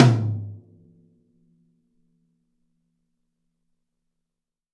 Index of /kb6/Akai_MPC500/1. Kits/Funk Set
M_TOM.WAV